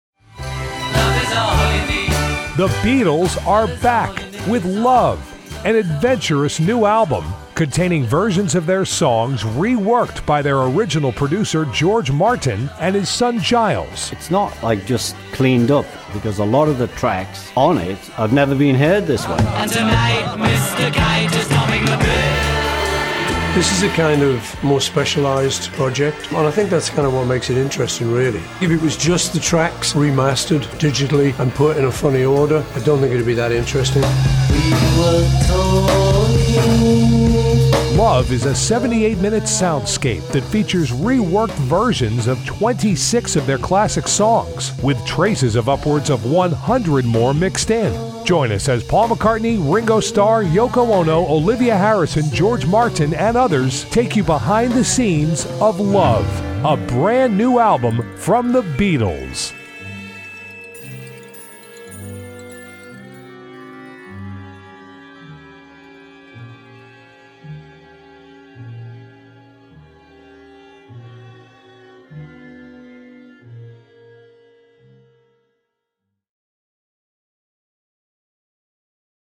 It does have additional material to the official “Interview Disc” in that there are comments on “Love” by Olivia Harrison and Yoko Ono in addition to Paul, Ringo, and George and Giles Martin.